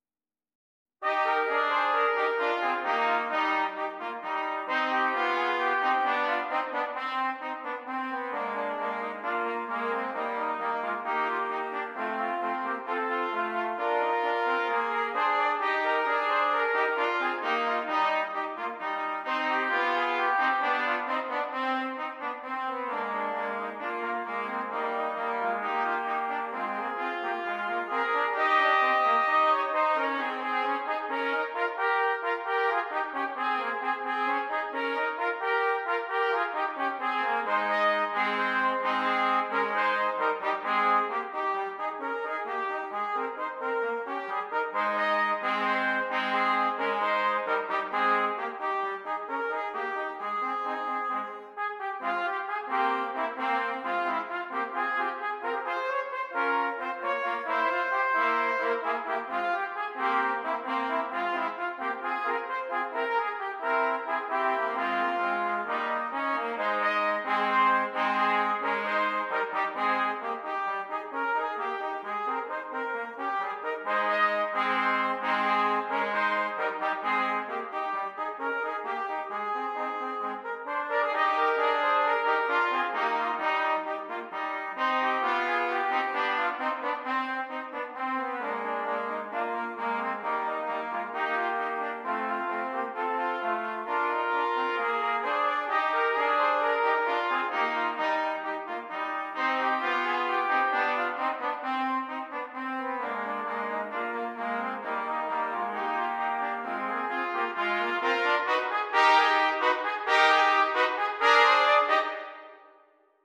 3 Trumpets